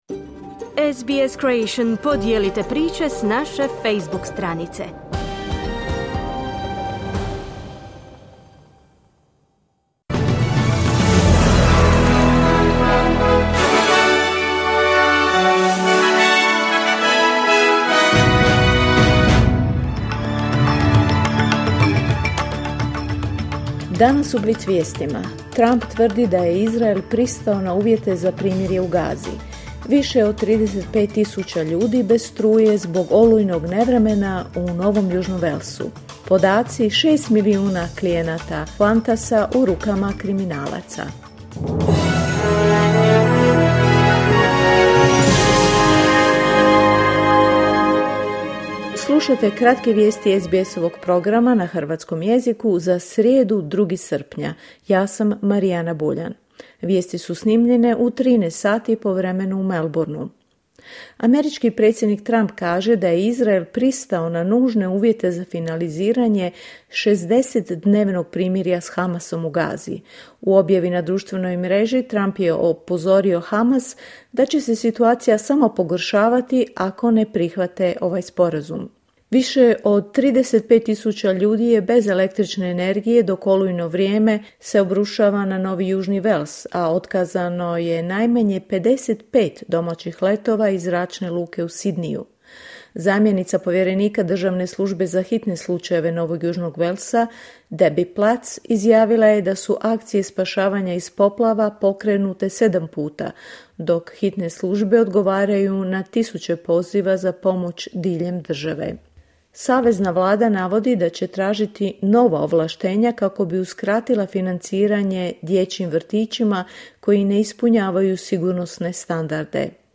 Vijesti radija SBS.